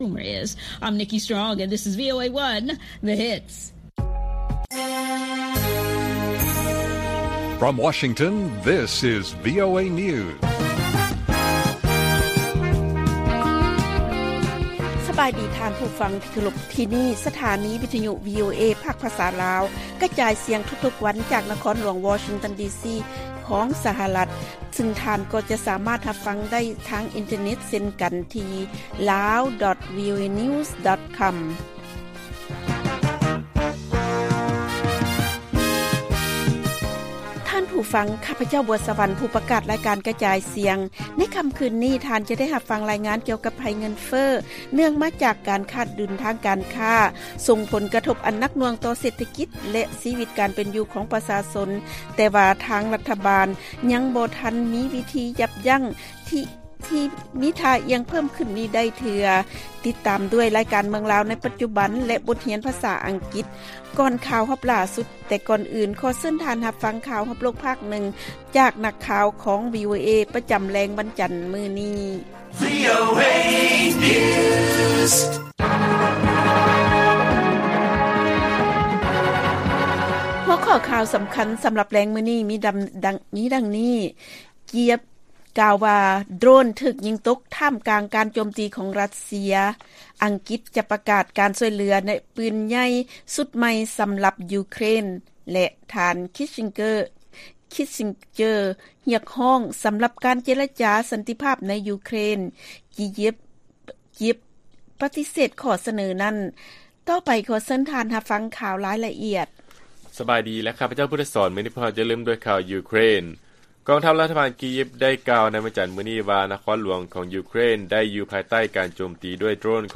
ລາຍການກະຈາຍສຽງຂອງວີໂອເອ ລາວ: ກີຢິບ ກ່າວວ່າ ໂດຣນຖືກຍິງຕົກ ທ່າມກາງການໂຈມຕີຂອງ ຣັດເຊຍ